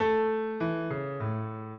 piano
minuet7-9.wav